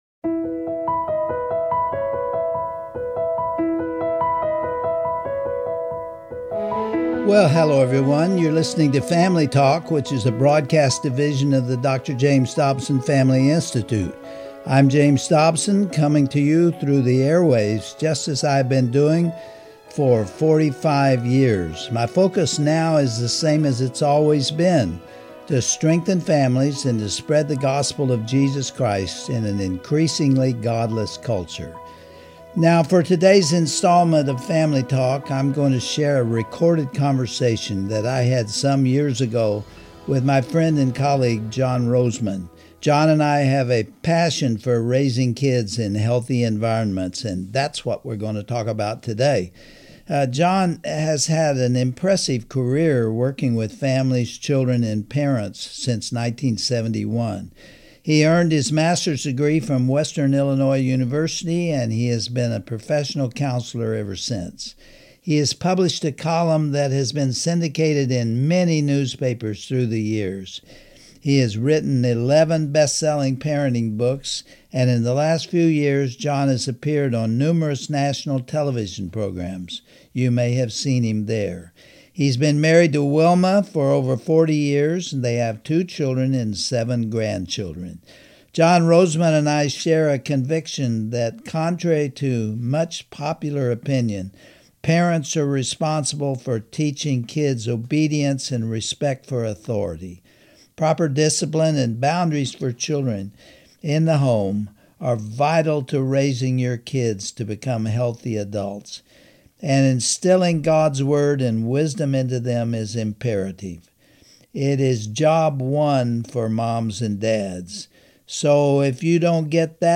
On today's edition of Family Talk, Dr. James Dobson and his guest, John Rosemond, explain how this change in focus has been a disaster.